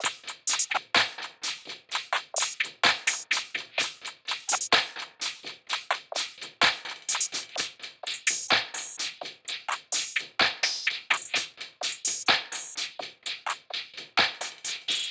perc loop maestro.wav